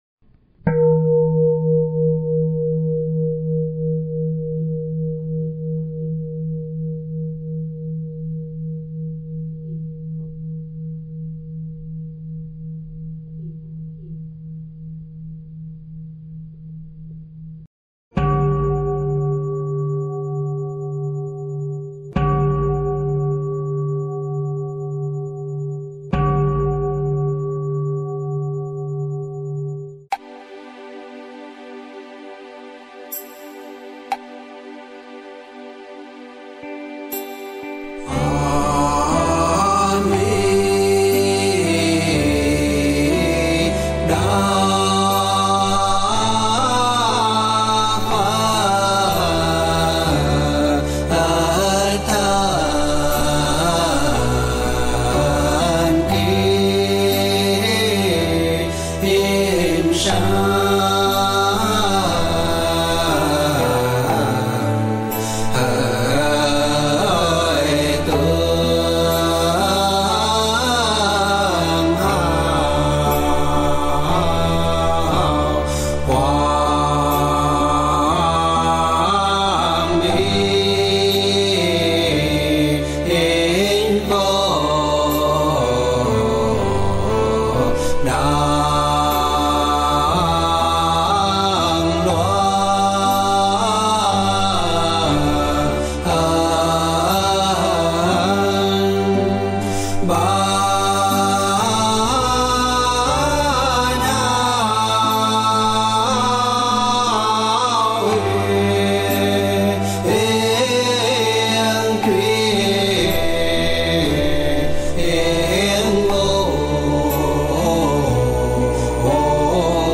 Nhạc Niệm Phật
Thể loại: Nhạc Niệm Phật